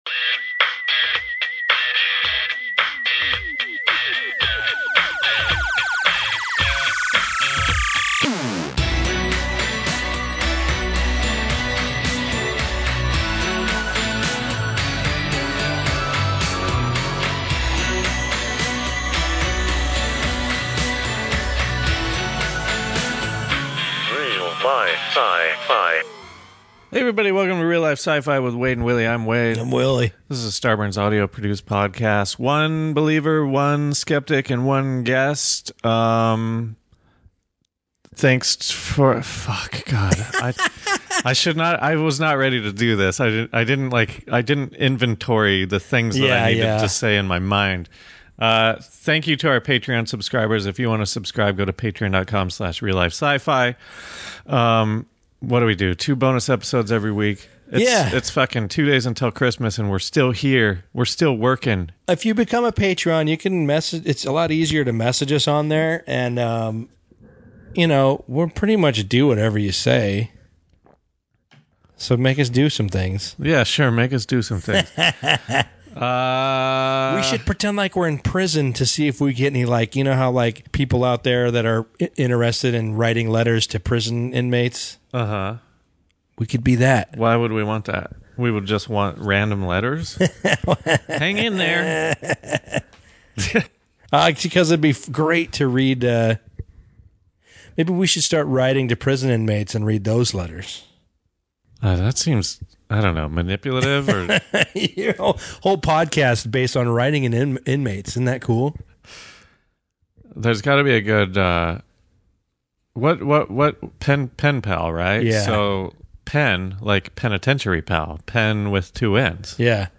I apologize but i didn't fully edit the bonus part of the episode so it doesn't sound as good - but don't look a gift horse in the mouth, right?